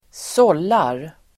Uttal: [²s'ål:ar]